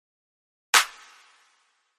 Single Clap
Single Clap is a free foley sound effect available for download in MP3 format.
301_single_clap.mp3